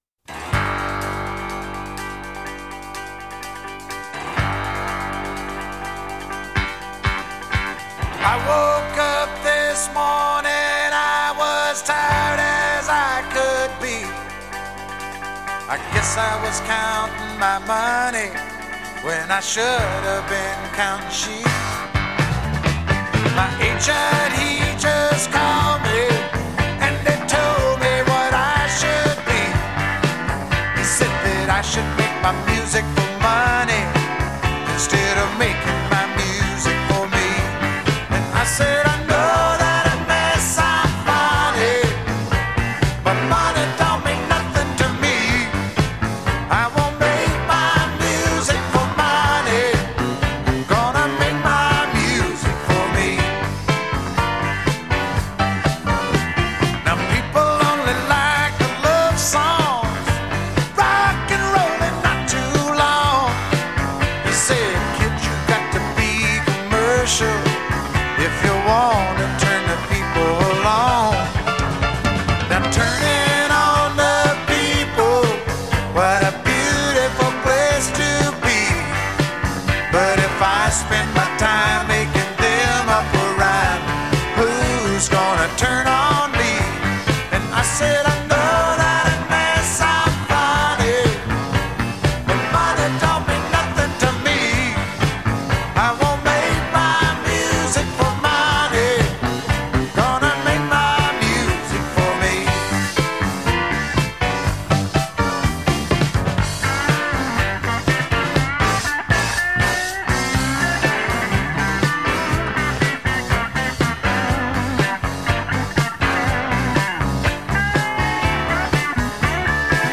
The album mixed country and pop sounds